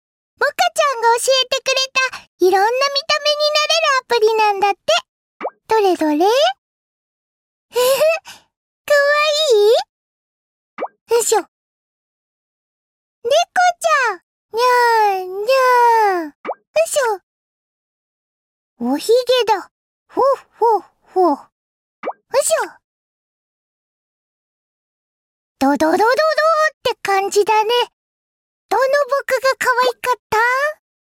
boka chan oshiete kureta - dore dore. How shibas sound when they get angry 😤